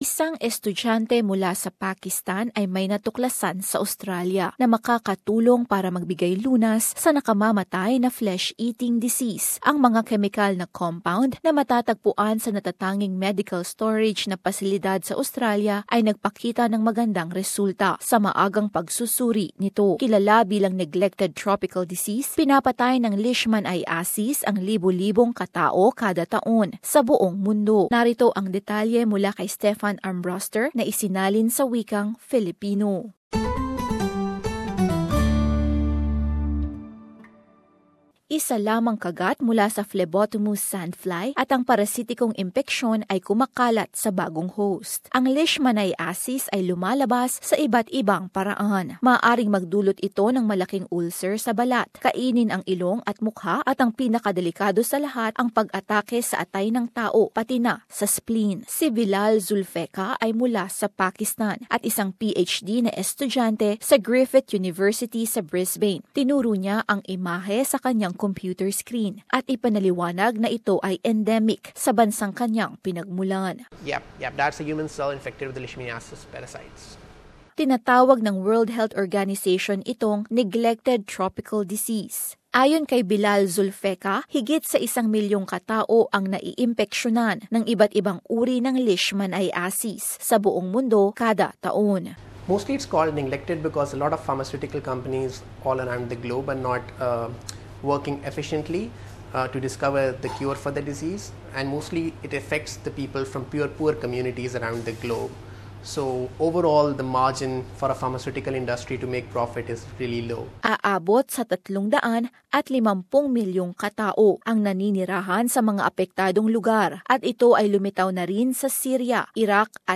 Image: A 13-year-old girl in Mexico City, Mexico, suffering from Leishmaniasis (AAP) Known as a neglected tropical disease, leishmaniasis [LEESH-man-EYE-a-siss] kills tens of thousands of people a year around the world.